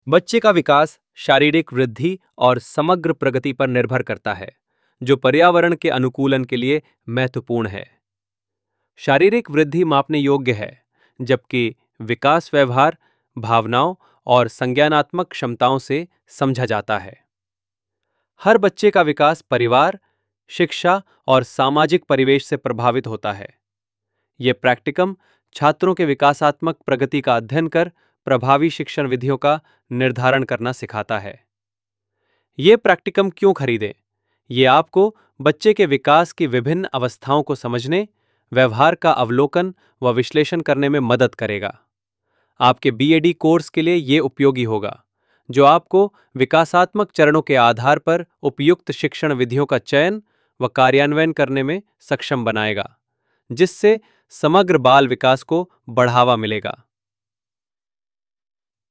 A short audio explanation of this file is provided in the video below.